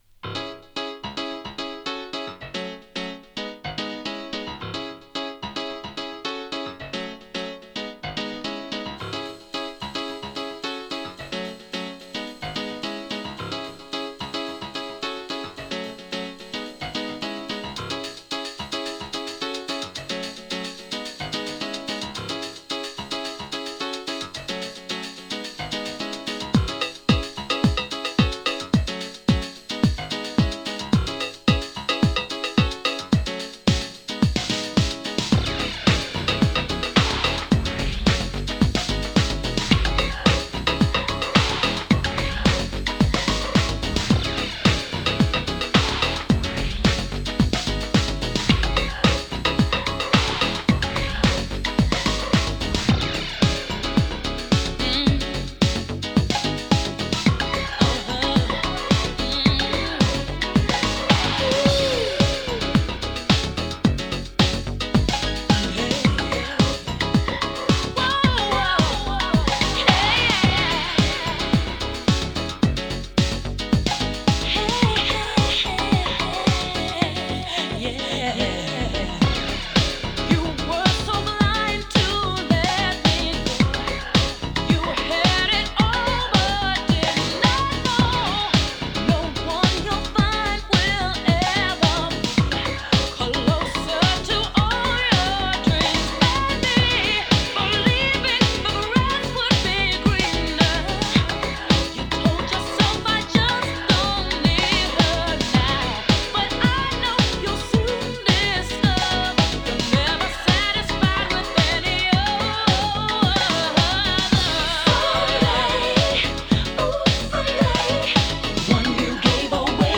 切なくも力強さを感じさせる90s RnB！